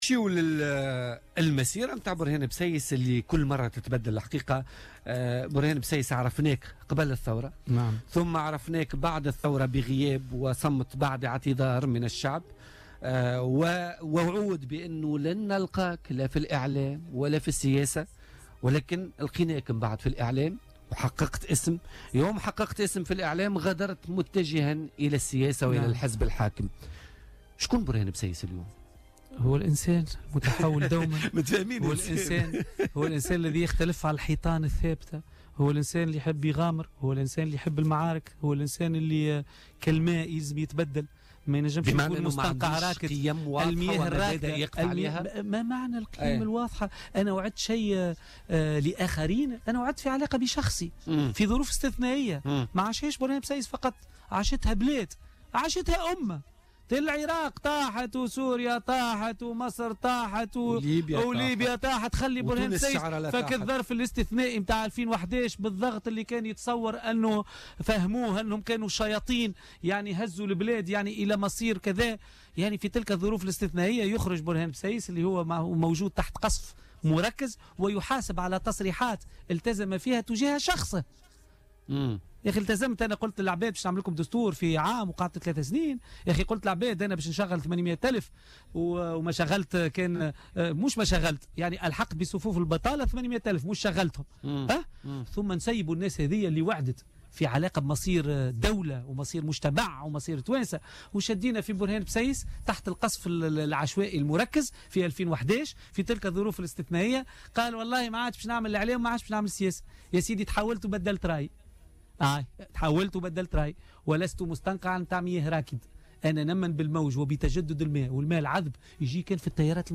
أكد الإعلامي برهان بسيس الذي انضم مؤخرا لحركة نداء تونس ضيف بولتيكا اليوم...